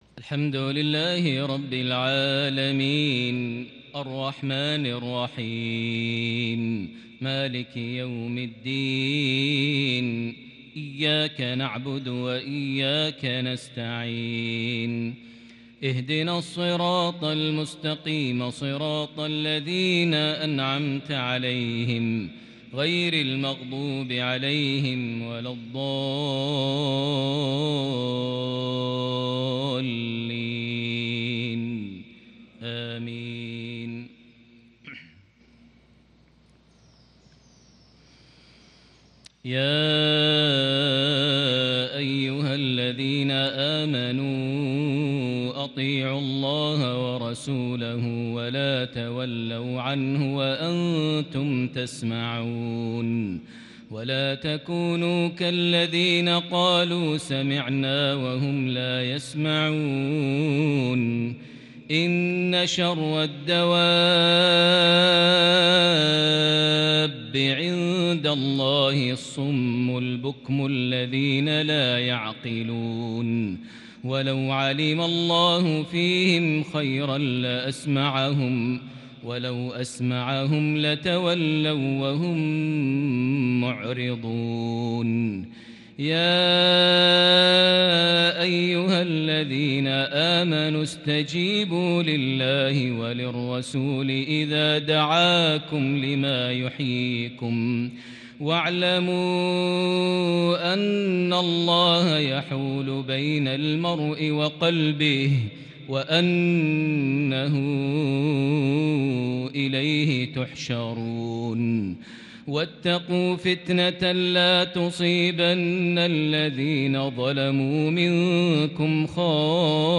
صلاة الفجر من سورة الانفال الإثنين 19 محرم 1442هـ | Surah Al-Anfal fajr 7-9-2020 prayer from > 1442 🕋 > الفروض - تلاوات الحرمين